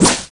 resident_stab_miss.wav